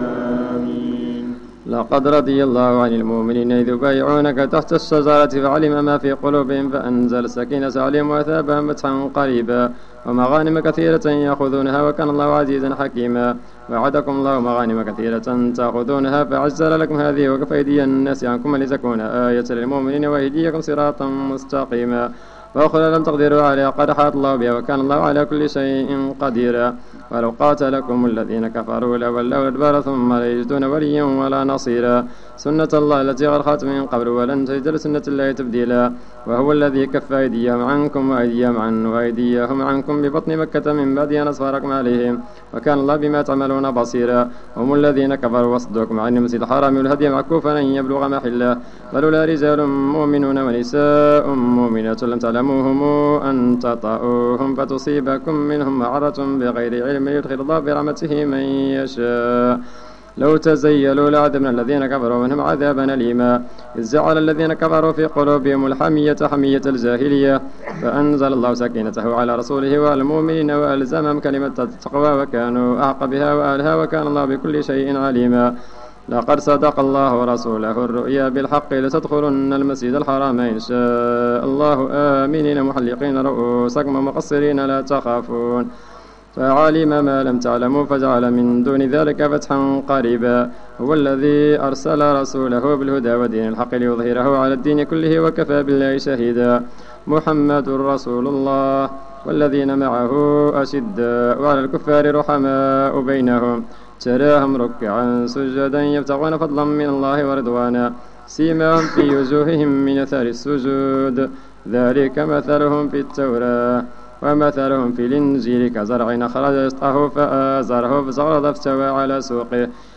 صلاة التراويح ليوم 25 رمضان 1431 بمسجد ابي بكر الصديق ف الزو
صلاة رقم 04 ليوم 25 رمضان 1431 الموافق سبتمبر 2010